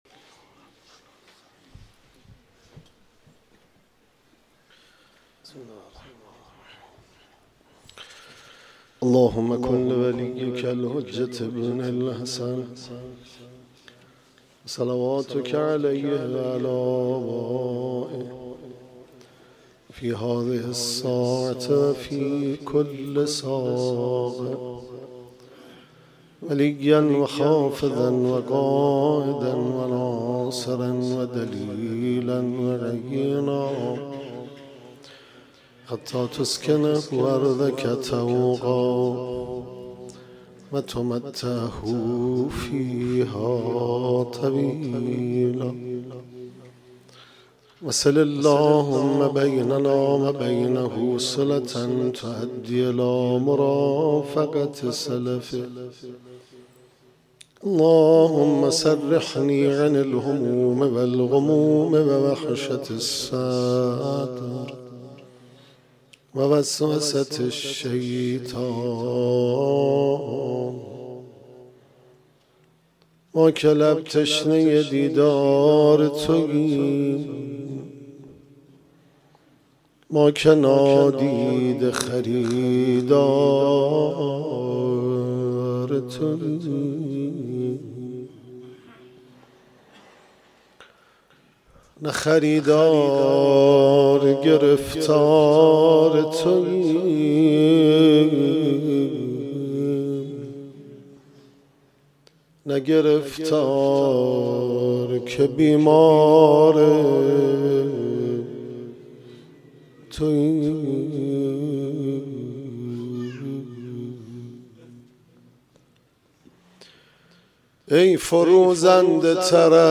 سخنرانی
مرثیه سرایی